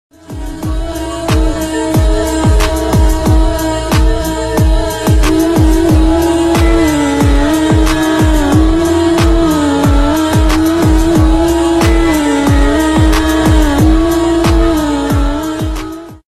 AAAH sound effects free download